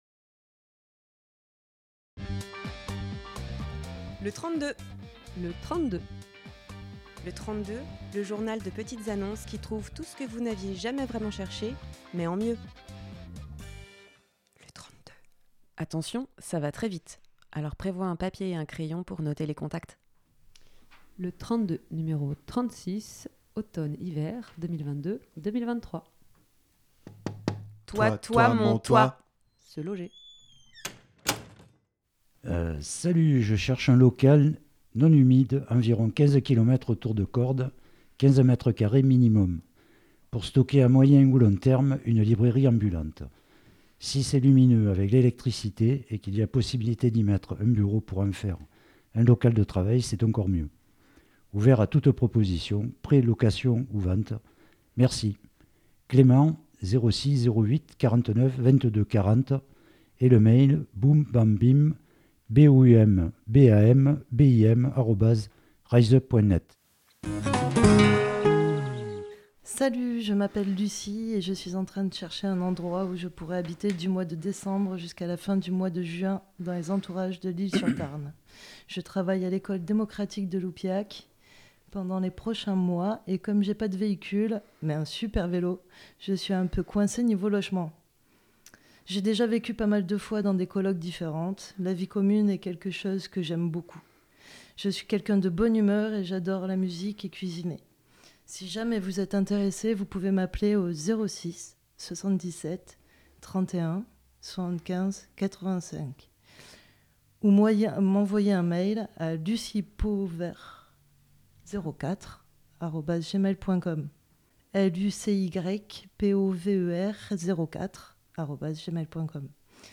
par quatre voix dans les locaux et avec l'aide de Radio OCTOPUS